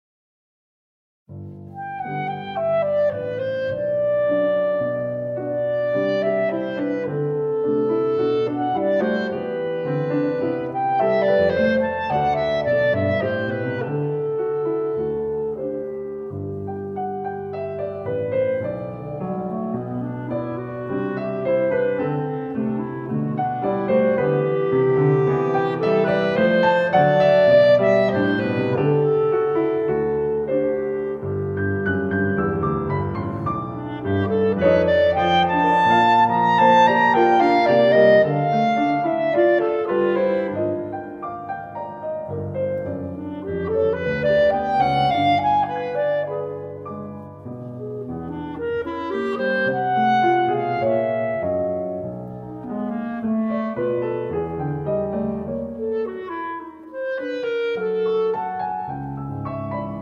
clarinet
piano